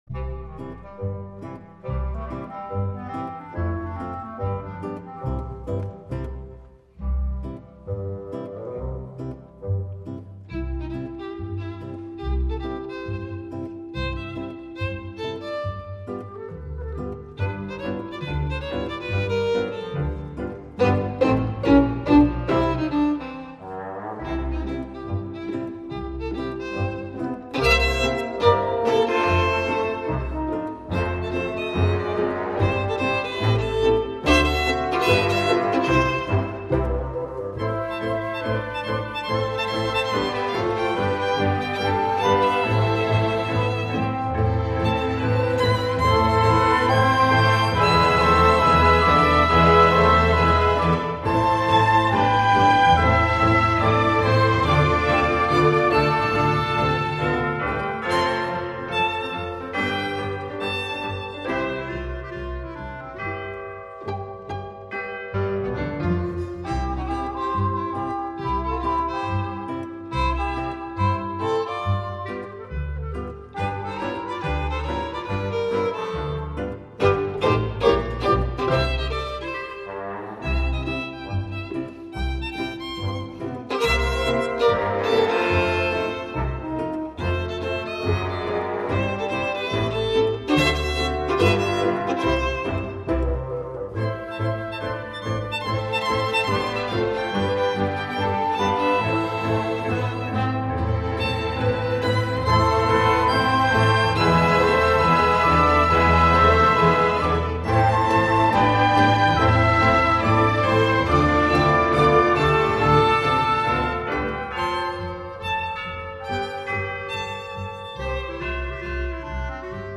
rozmawiała z Vadimem Brodskim